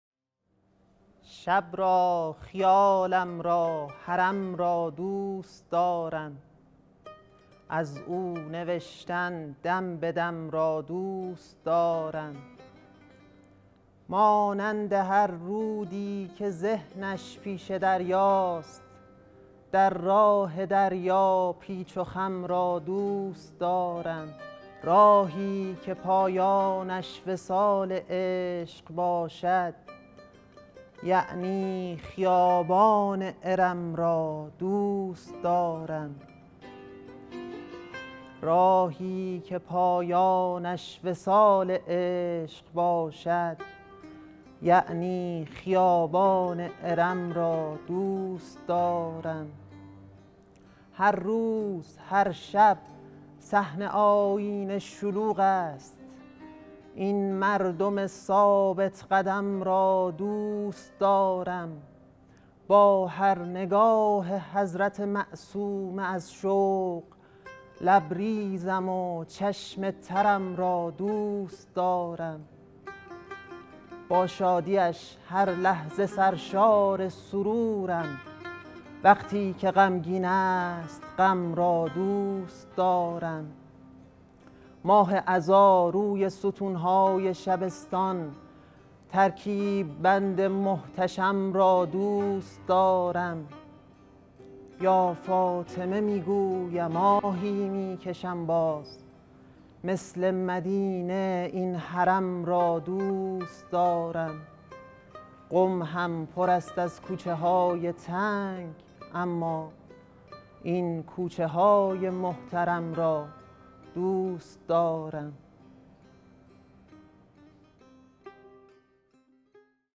شعرخوانی
حرم حضرت معصومه (سلام الله علیها)_شهر مقدس قم | روایت هیأت فصل دوم